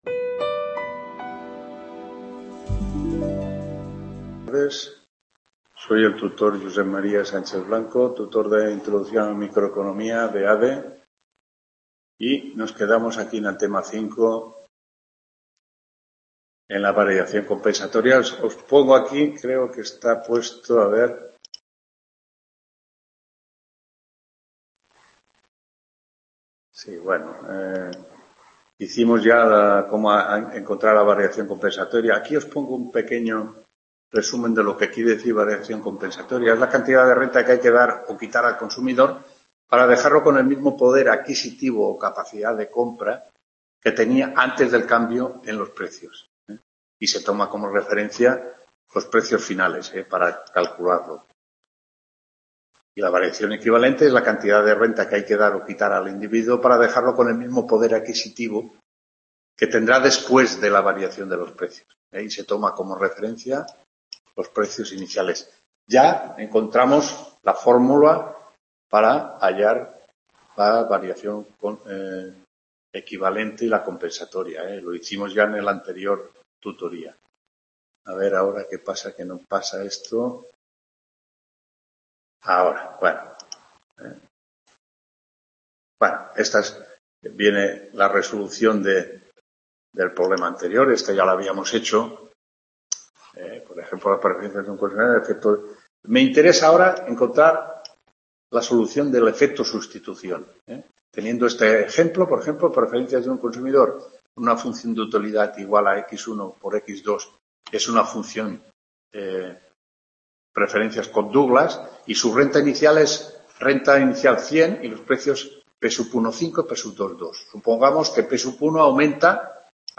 10ª TUTORÍA INTRODUCCIÓN A LA MICROECONOMÍA (ADE) 21… | Repositorio Digital